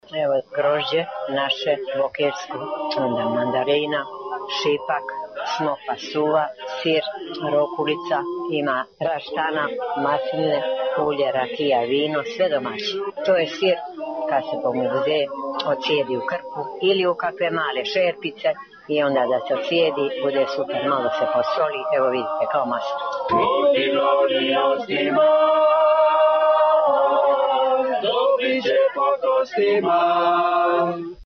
Brojne su fešte na crnogorskom primorju koje slave mediteranske ukuse. Evo nas na jednoj u Gornjoj Lastvi:
Fešta u Gornjoj Lastvi